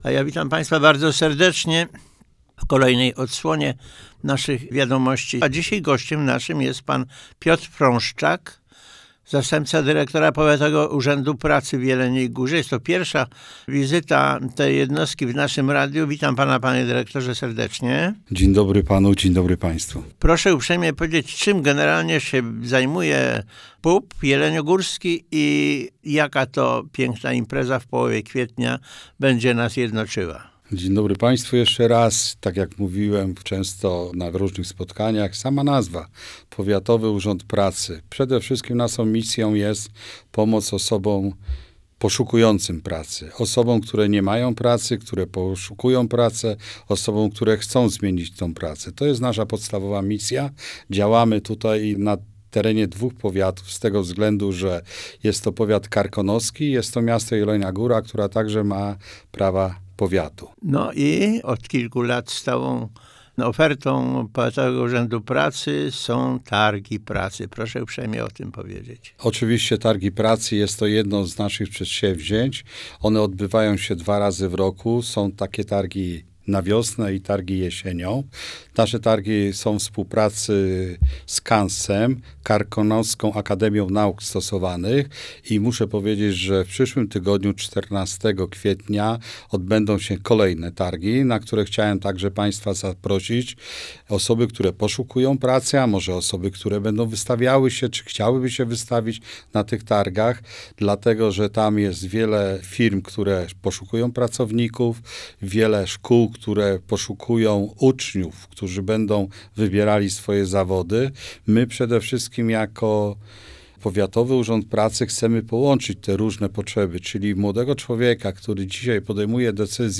Kolejna audycja na antenie Radia KSON poświęcona była działalności Powiatowego Urzędu Pracy w Jeleniej Górze oraz organizowanym przez tę instytucję targom pracy.